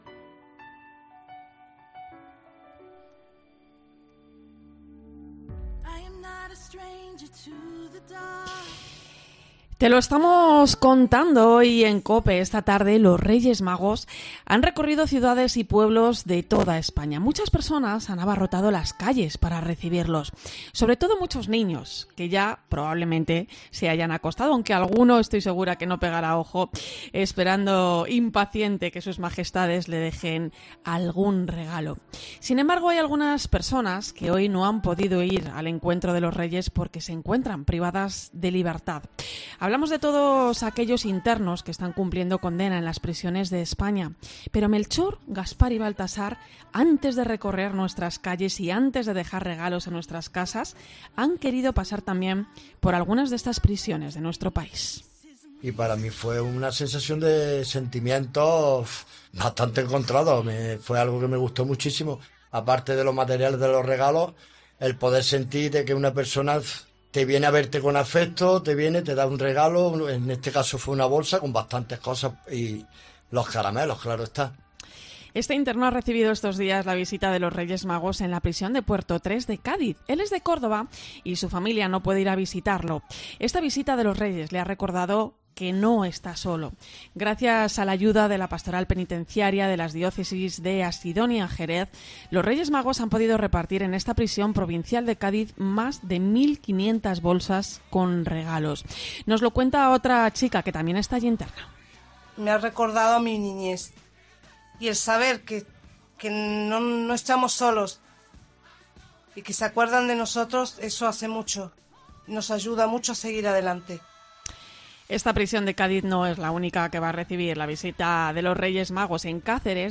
Nos lo cuenta otra chica que también está allí interna.